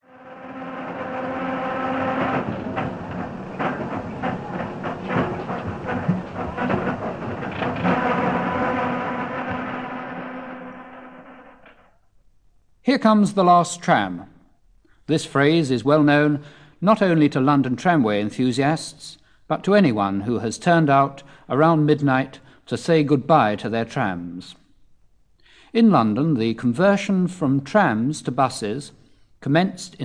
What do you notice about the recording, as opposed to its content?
Londons last Trams Stage 1 Live recordings